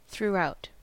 Ääntäminen
Ääntäminen US Tuntematon aksentti: IPA : /θɹuːˈaʊt/ Haettu sana löytyi näillä lähdekielillä: englanti Käännös Adverbit 1. überall 2. hindurch Määritelmät Prepositiot In every part of; all through.